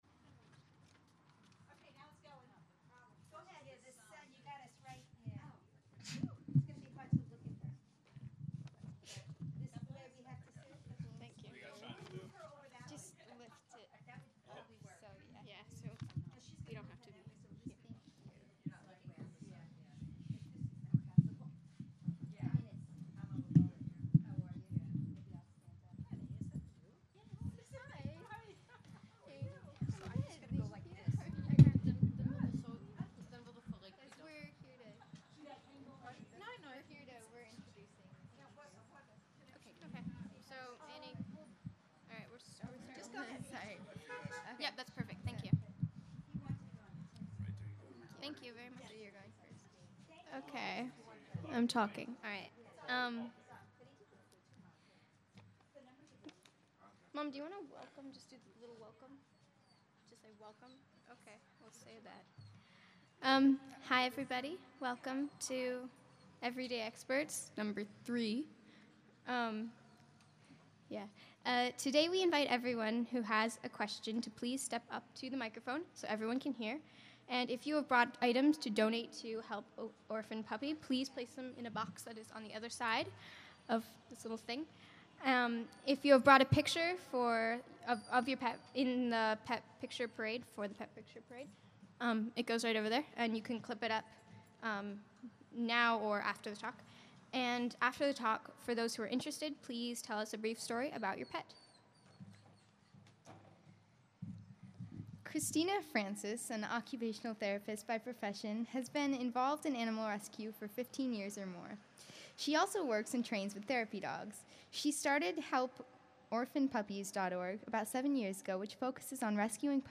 spoke at the "Everyday Experts" lecture series at the Chatham Gazebo.